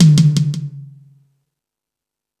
Space Drums(28).wav